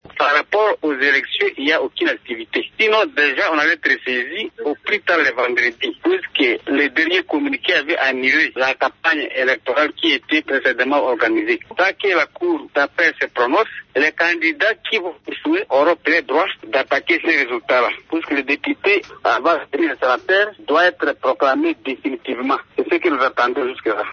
Les candidats sénateurs et les députés provinciaux se disent bloqués pour poursuivre le processus électoral. Le vice-président de l’assemblée provinciale de l’Ituri, Adaba Masumbuko, explique :